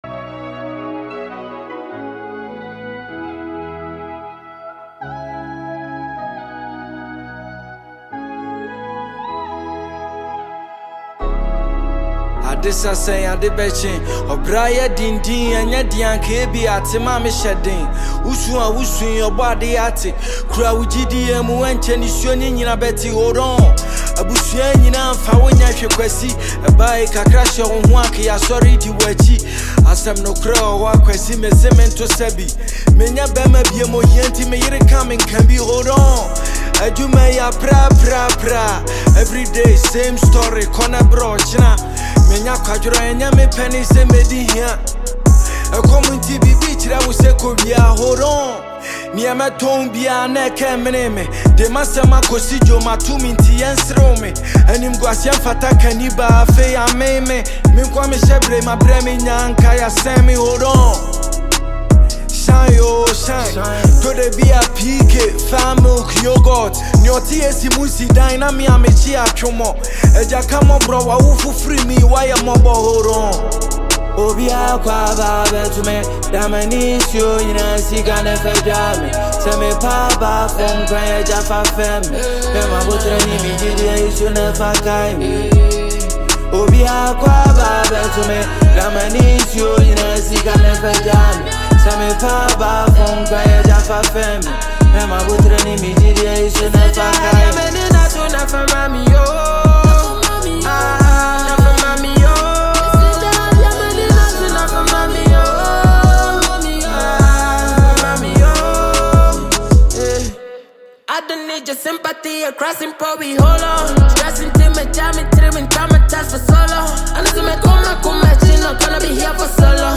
GHANA MUSIC
Ghanaian award-winning rapper